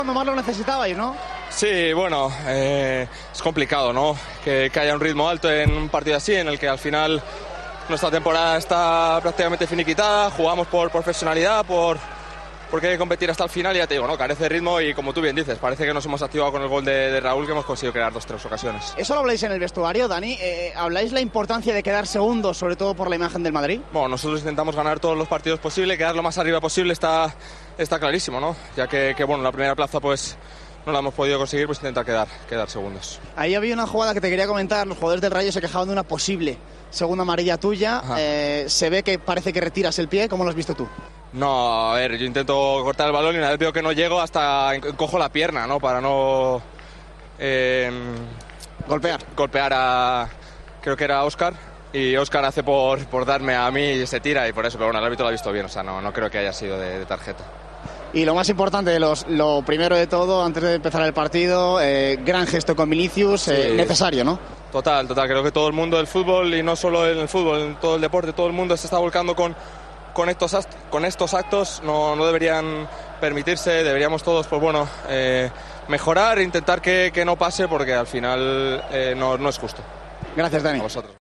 El lateral derecho del Real Madrid habló en los micrófonos de Movistar tras la victoria ante el Rayo Vallecano.